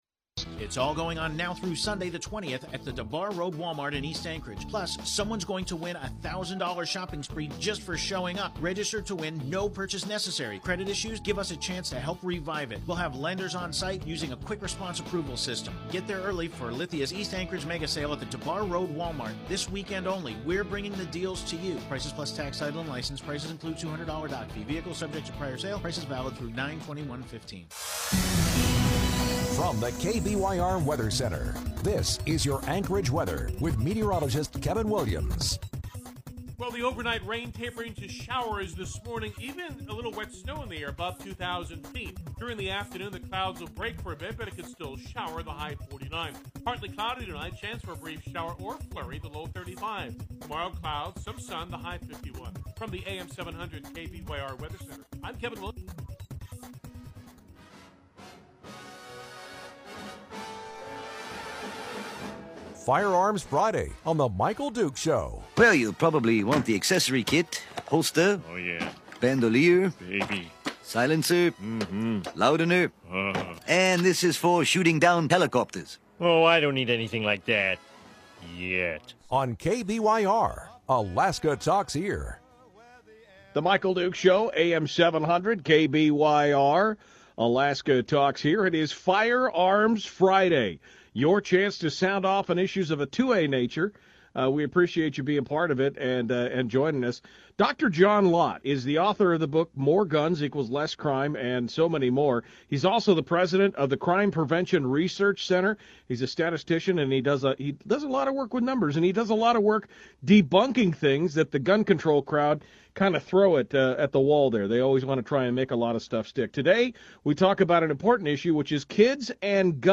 Anchorage, Alaska's big talk radio station KBYR about the benefits and risks of people having guns in their homes